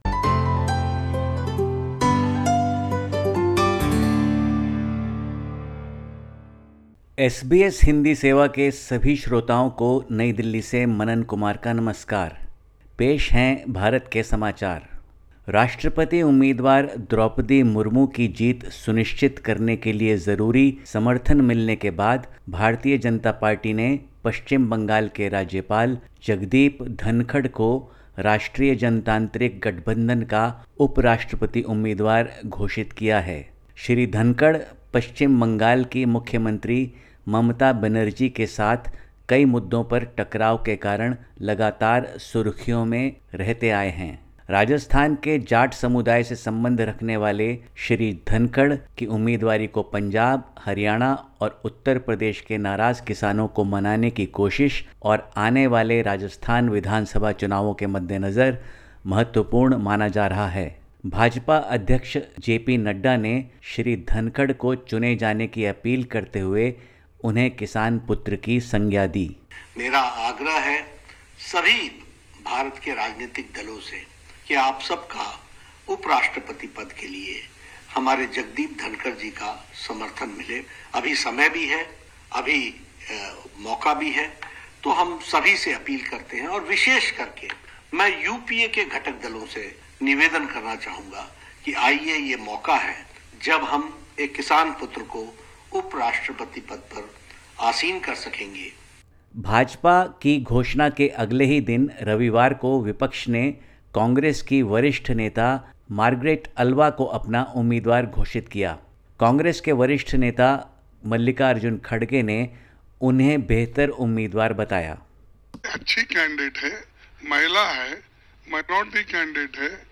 Listen to the latest SBS Hindi report from India. 18/07/2022